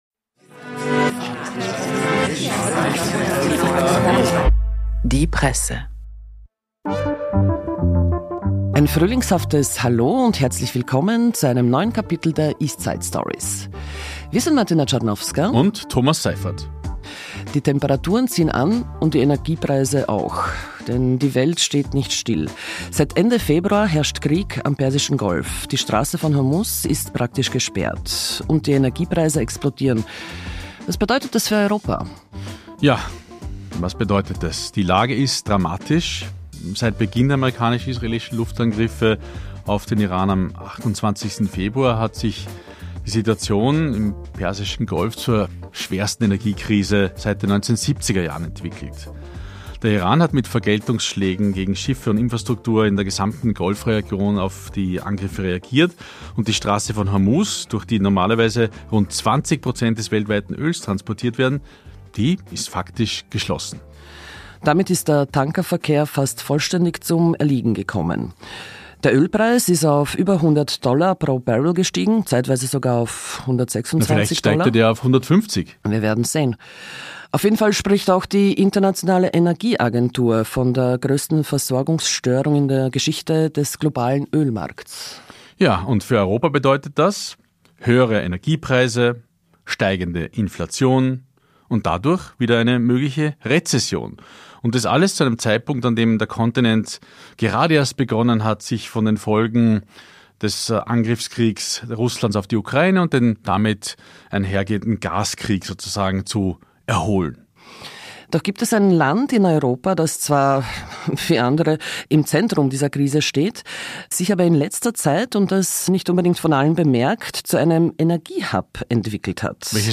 Beschreibung vor 1 Woche Am 12. April wählt Ungarn ein neues Parlament – und zum ersten Mal seit 2010 könnte Viktor Orbán abgewählt werden. Die grüne Abgeordnete Tímea Szabó über die Hoffnung auf einen Machtwechsel, die bittere Lage progressiver Kräfte und eine Warnung an ganz Europa. Dazu: eine Sprachnachricht aus Athen zur schwersten Energiekrise seit den 1970er-Jahren.